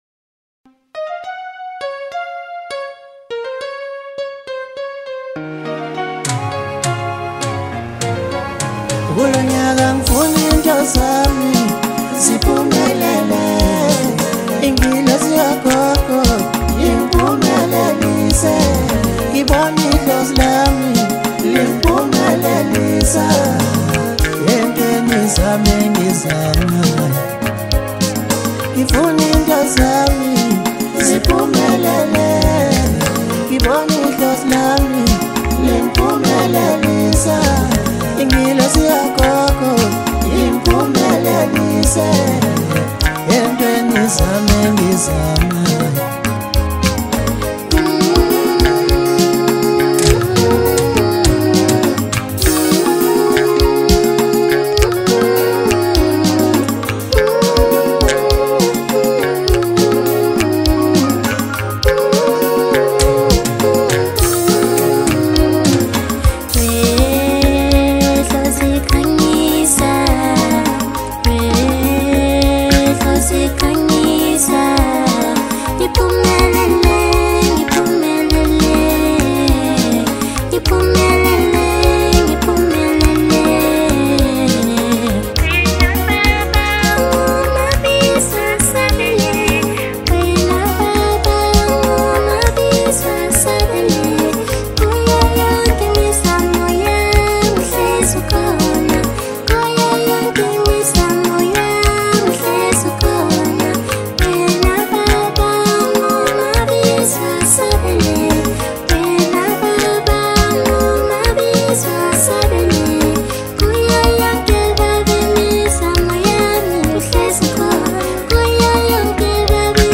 Maskandi, DJ Mix, Hip Hop